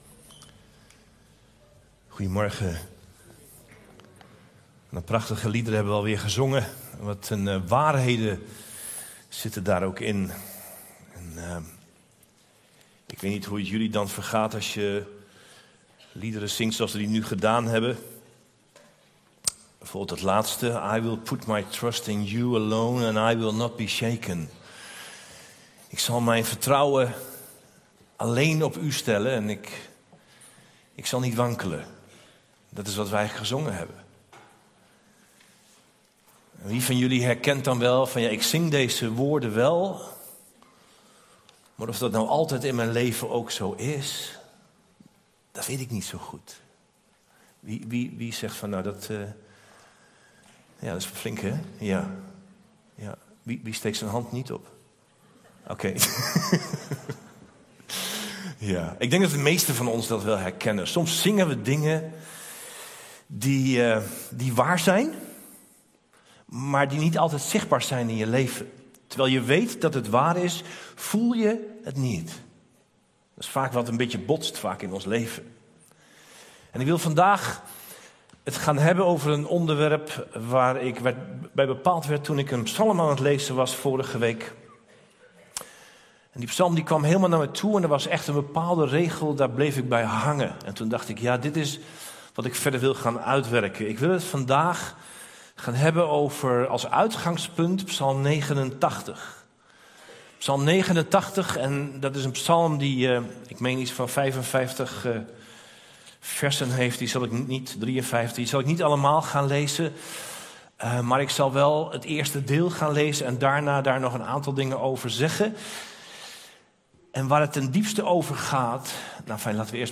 Preek-3-december-2023.mp3